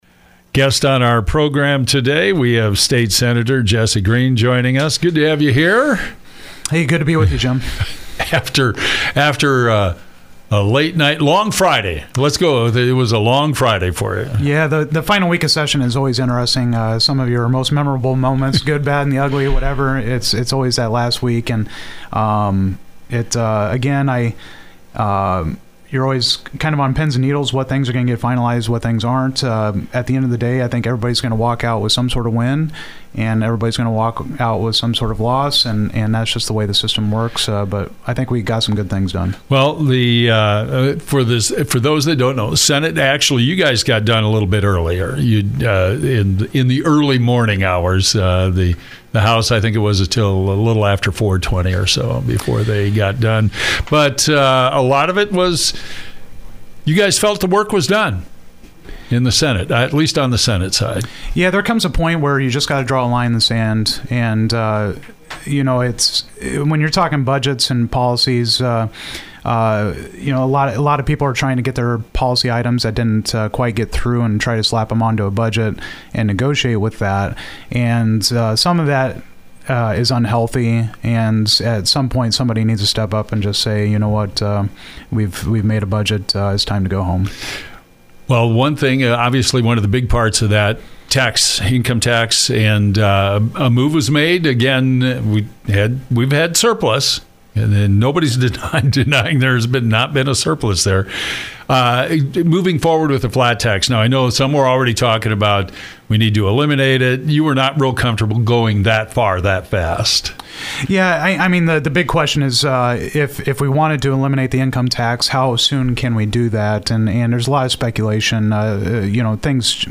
State Senator Jesse Green (R) Boone, Iowa